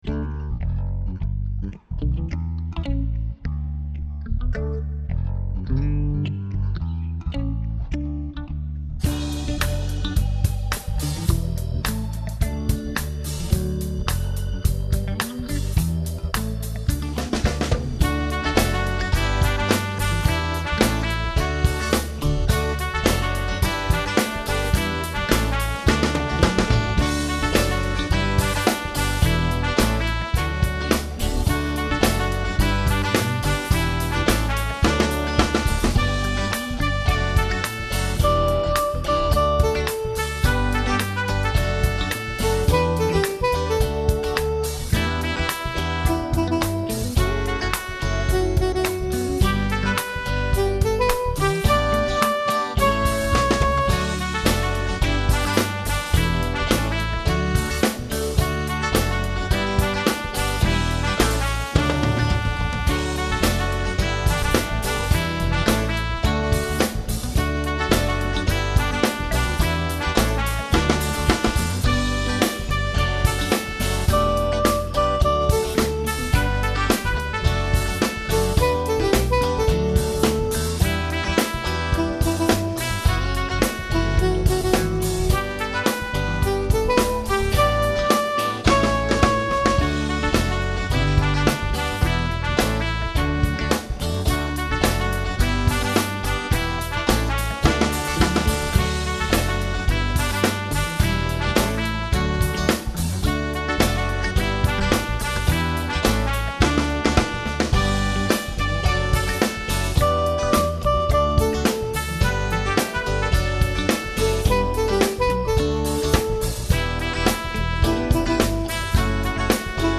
I, too, have gone the funk route.